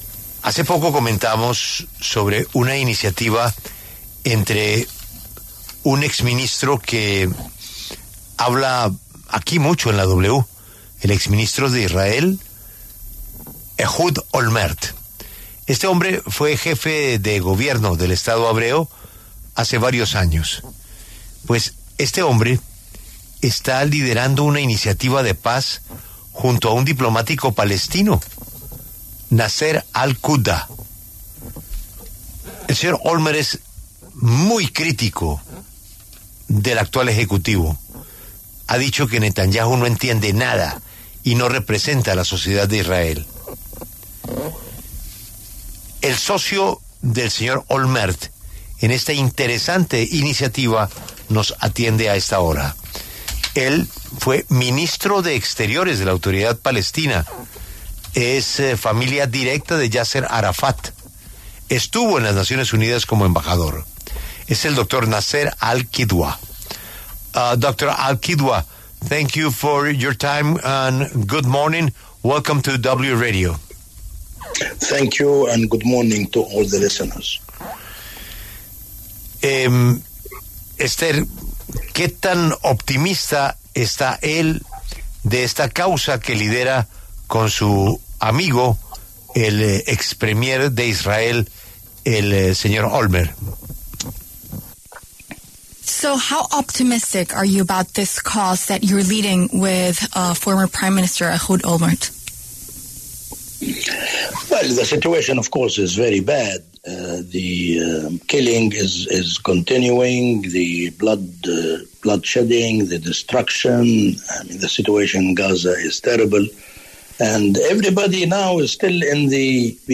La W conversó con el Dr. Nasser Al-Kidwa, quien propone un intercambio de una porción de tierra entre Israel y la Franja de Gaza como una solución al conflicto en Medio Oriente.